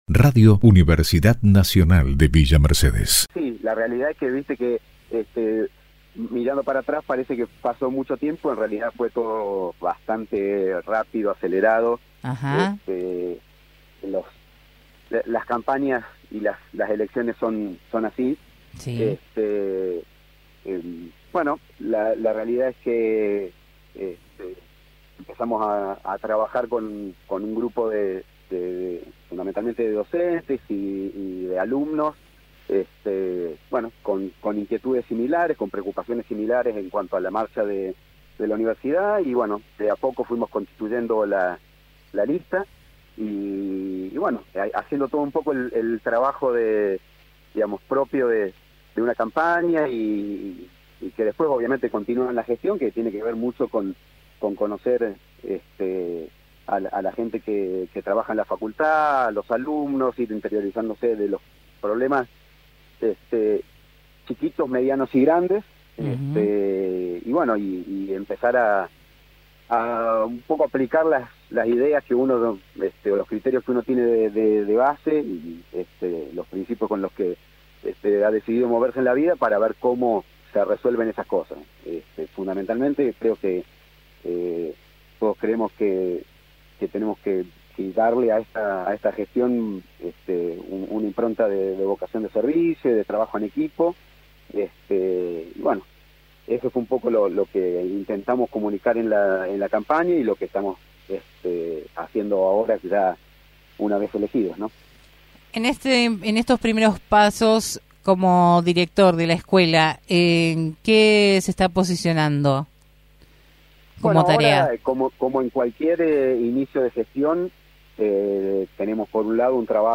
En diálogo con el programa «Agenda Universitaria», comentó cómo se gestó la idea de formar parte de la dirección de dicha escuela y los alcances, anhelos y proyectos para mejorar la calidad y la formación, para luego poder insertarse en el medio laboral.